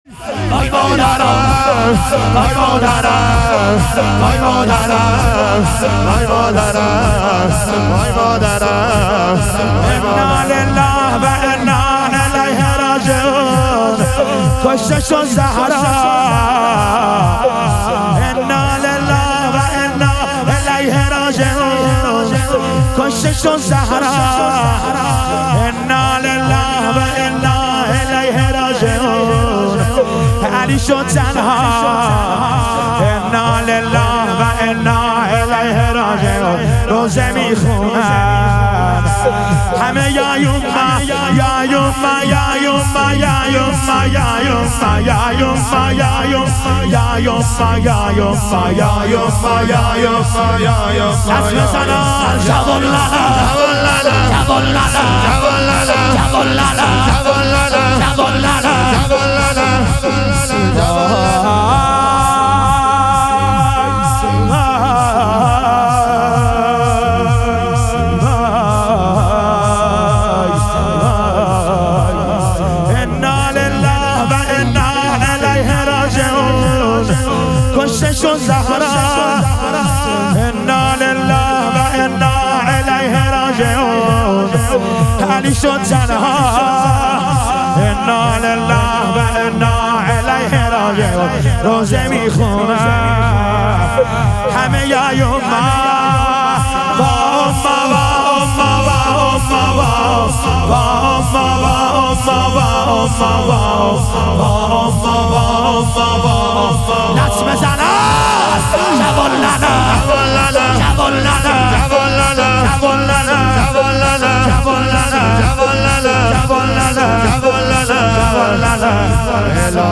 شام غریبان حضرت زهرا علیها سلام - لطمه زنی